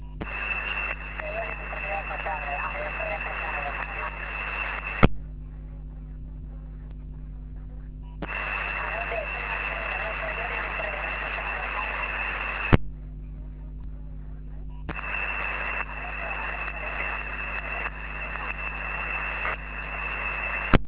Meteor Scatter
But the activity was great, some pile-ups, QRM as usually, so I worked about 100 QSOs, all random SSB.
Used rig: TCVR R2CW, PA 500W, ant: 4x9el (North-East), 4x4el (West) and 4x4el (South).